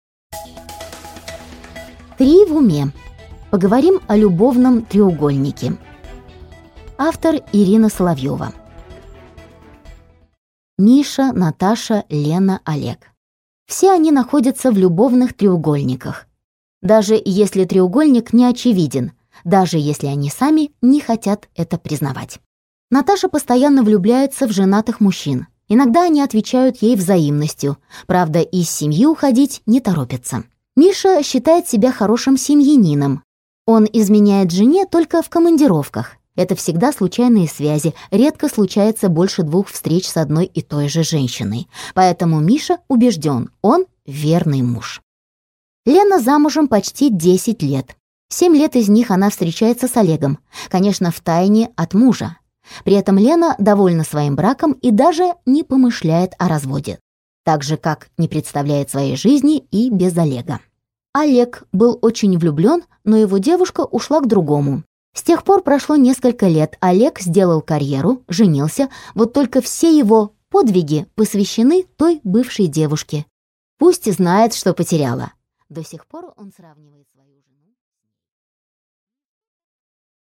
Аудиокнига Три в уме. Поговорим о любовном треугольнике | Библиотека аудиокниг